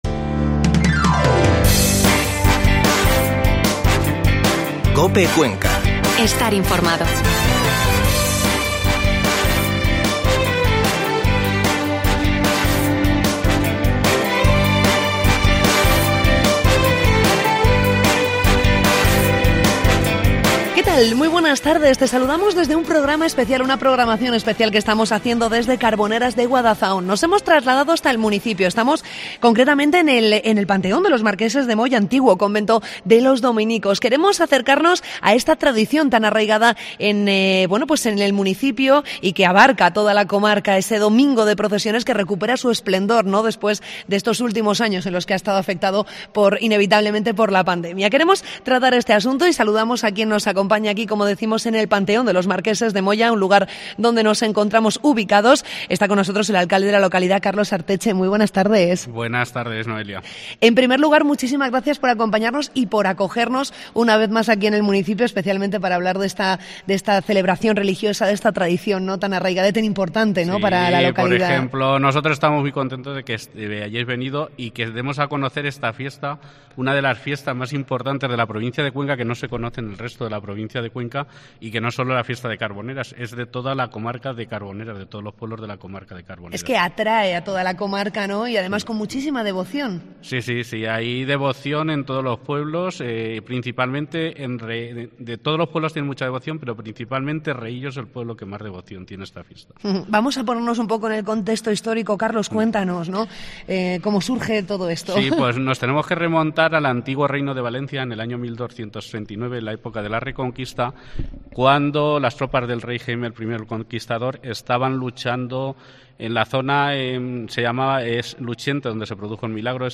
Mediodía COPE Cuenca desde Carboneras de Guadazaón